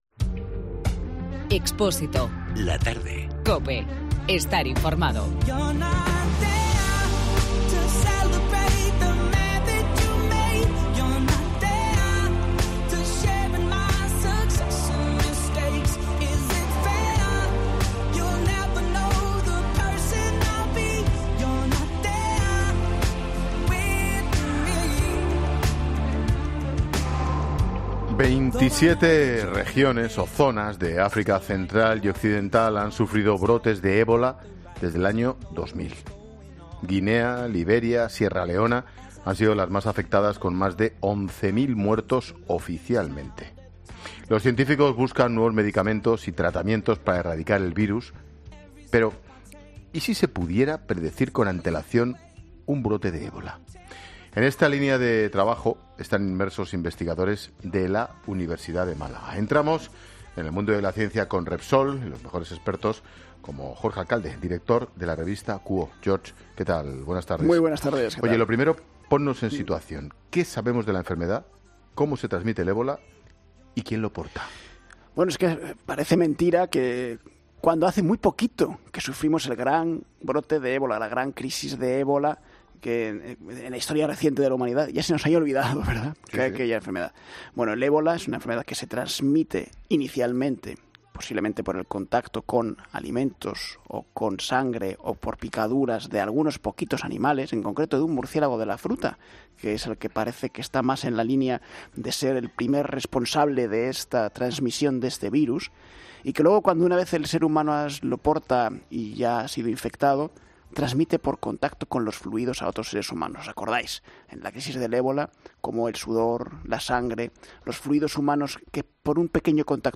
Las entrevistas